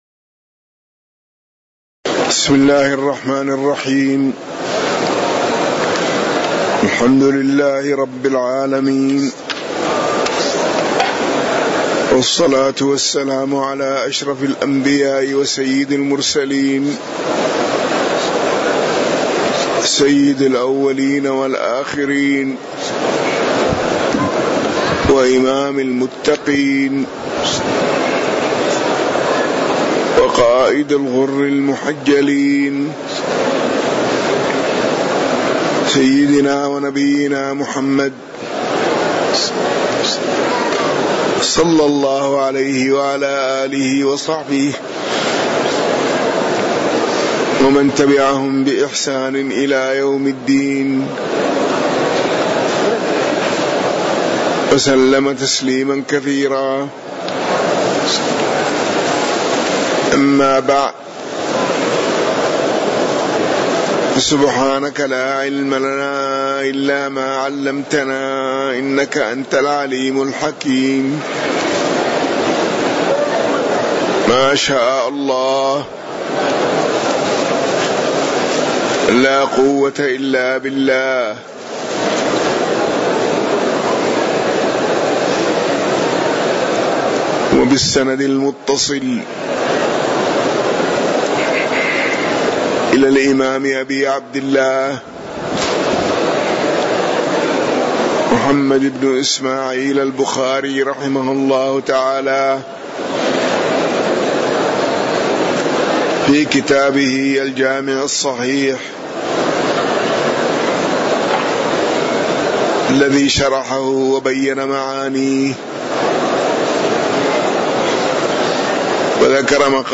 تاريخ النشر ٥ جمادى الآخرة ١٤٣٩ هـ المكان: المسجد النبوي الشيخ